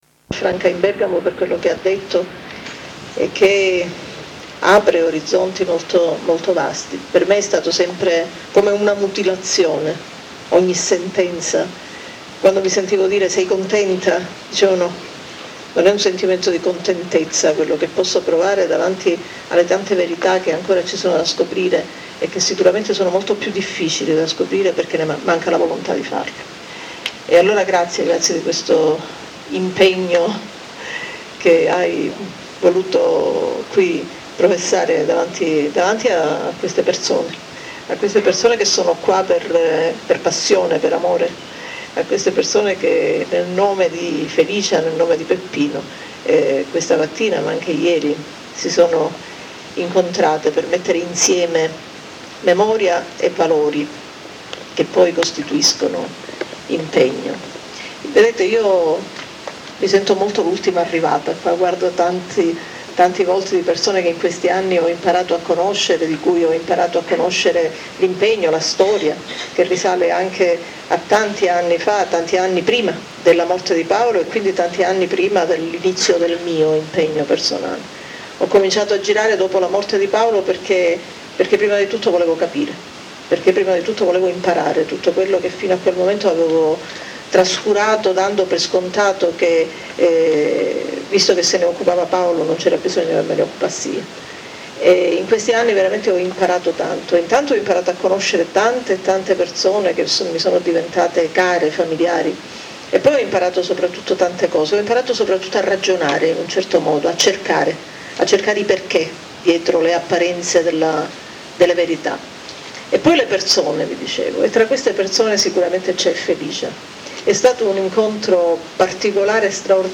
[ForumSocialeAntimafia] Audio dalla presentazione del libro "Cara Felicia"
l'intervento di Rita Borsellino